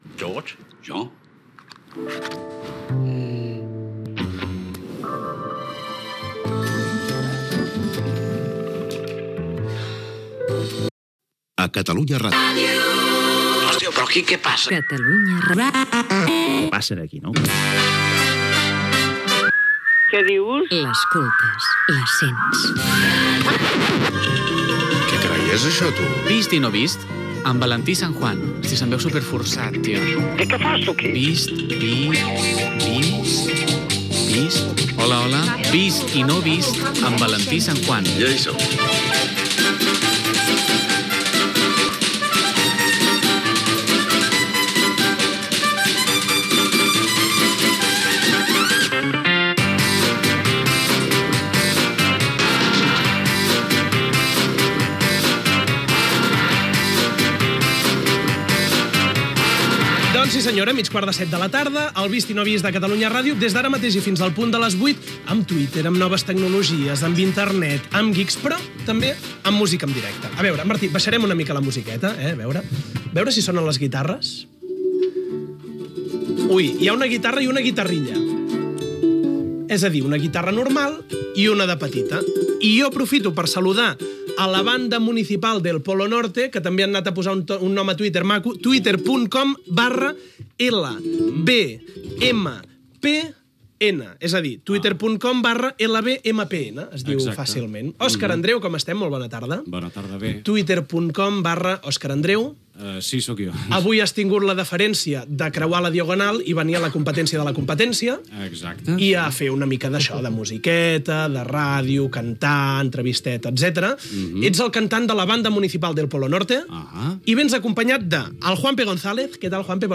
Careta del programa, hora, presentació de la Banda Municipal del Polo Norte amb paraules del seu cantant Òscar Andreu i la interpretació d'un tema a l'estudi
Entreteniment